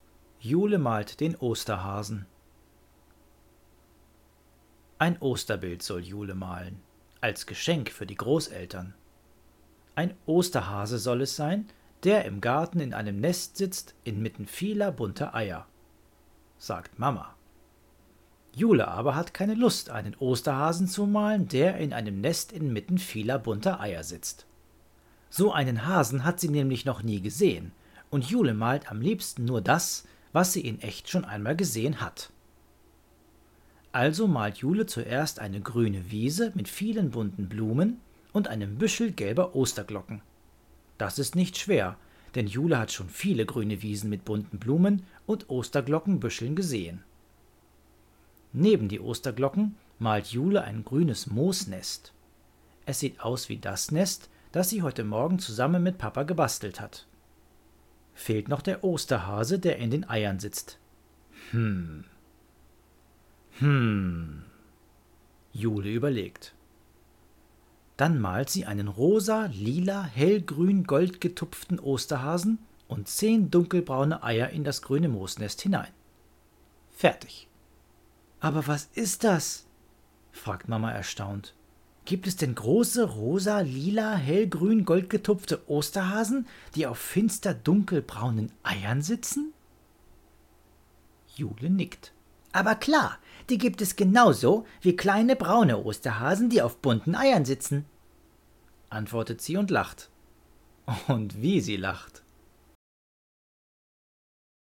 Hier erzählt dir